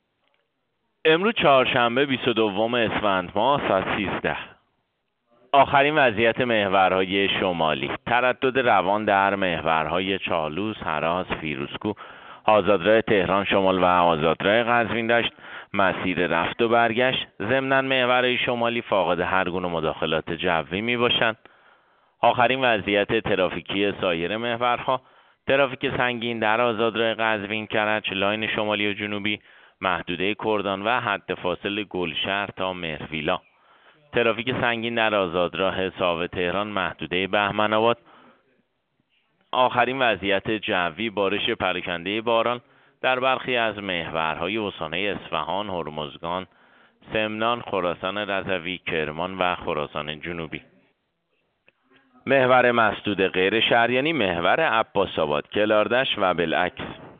گزارش رادیو اینترنتی از آخرین وضعیت ترافیکی جاده‌ها ساعت ۱۳ بیست و دوم اسفند؛